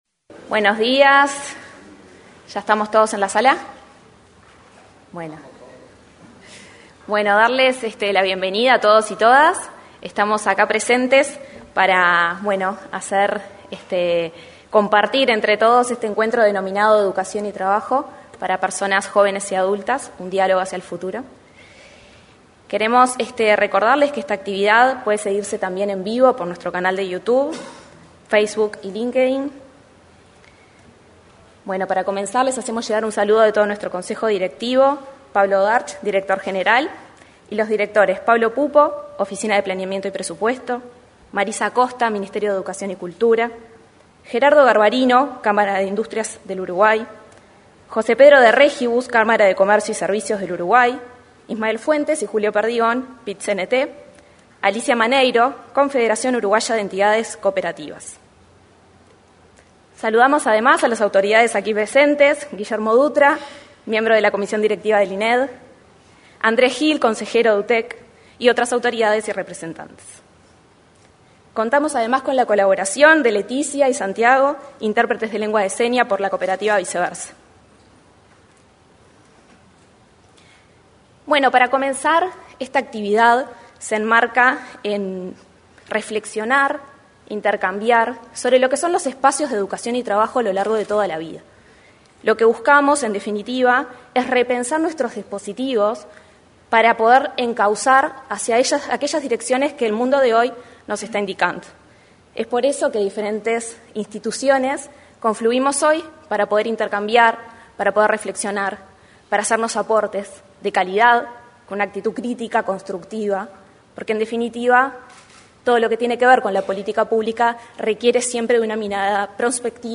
Un Diálogo hacia el Futuro 18/10/2024 Compartir Facebook X Copiar enlace WhatsApp LinkedIn Este viernes 18, se realizó, en el auditorio de la Torre Ejecutiva anexa, el encuentro Educación y Trabajo para Personas Jóvenes y Adultas. Un Diálogo hacia el Futuro.